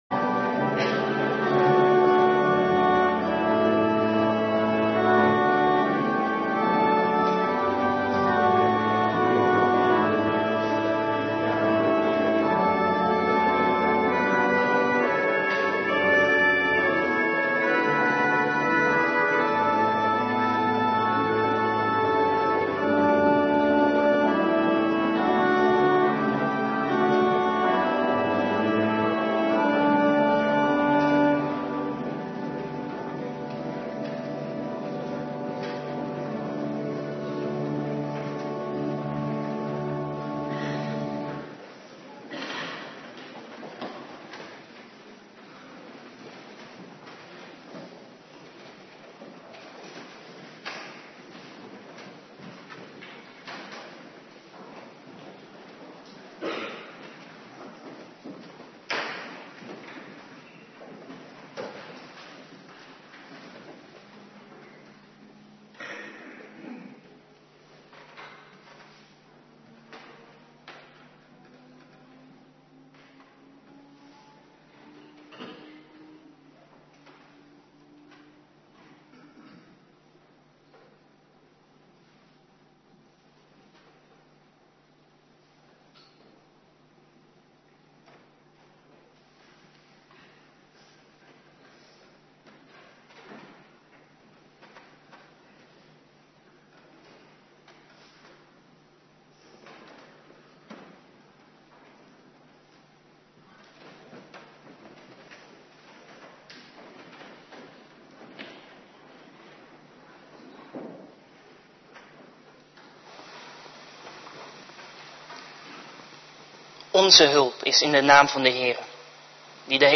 Avonddienst